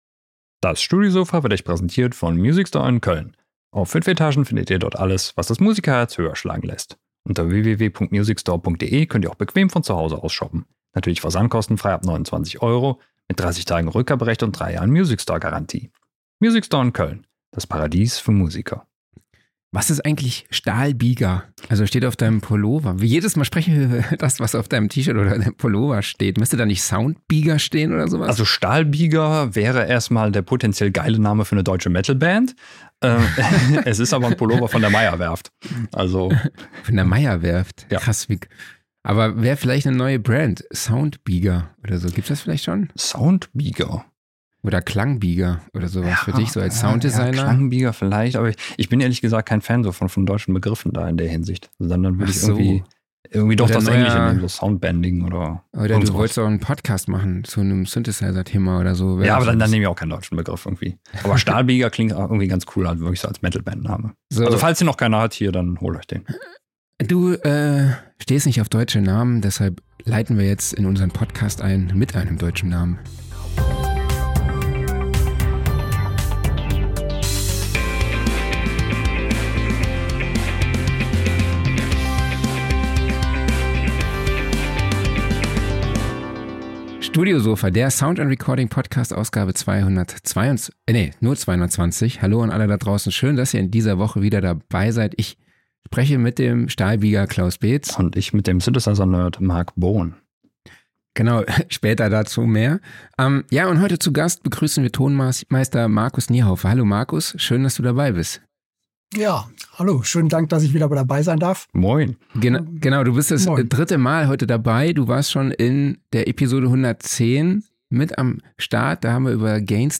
In this engaging interview